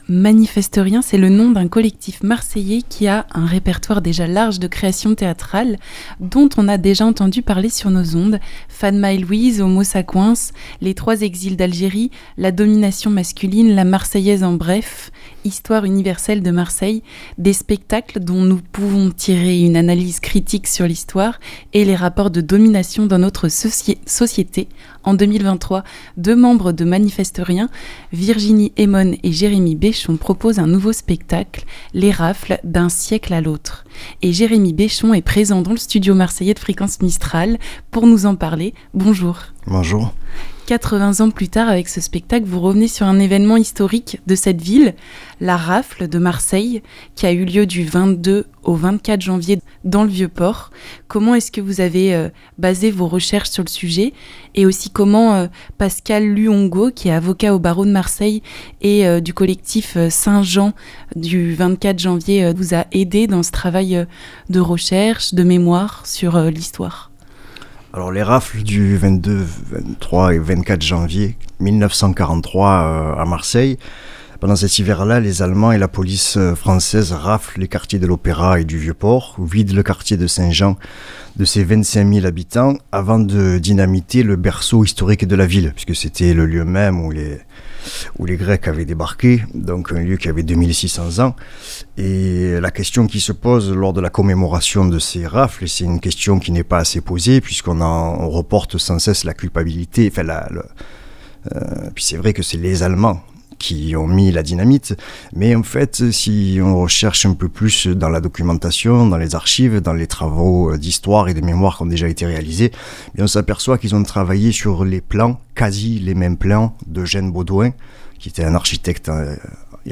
Pendant le Magazine du Midi du 6 janvier 2023